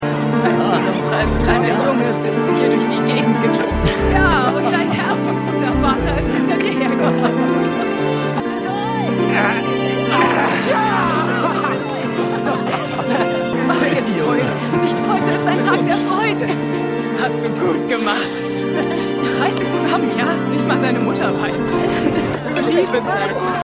Pachinko_2x07_FrauGepunktetesHemd.mp3